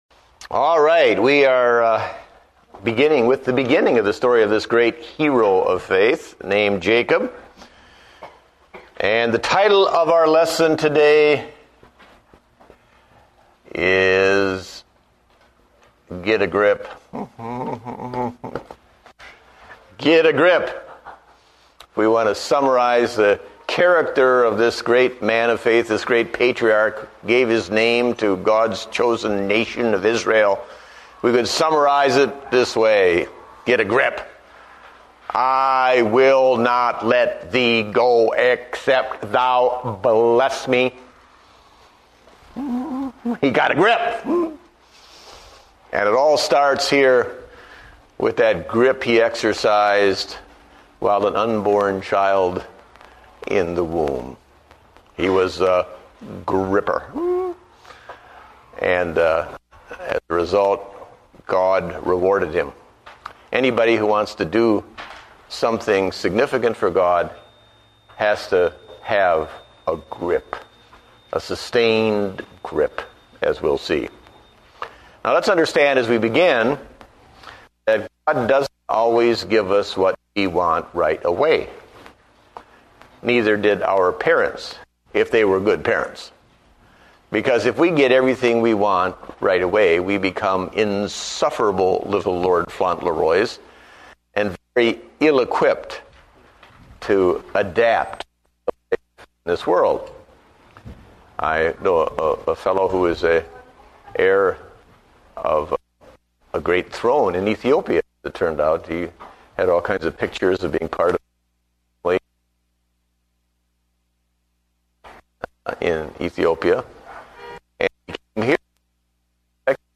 Date: April 5, 2009 (Adult Sunday School)